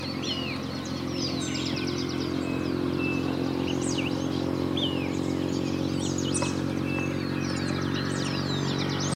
Crespín (Tapera naevia)
Nombre en inglés: Striped Cuckoo
Localidad o área protegida: Merlo
Condición: Silvestre
Certeza: Vocalización Grabada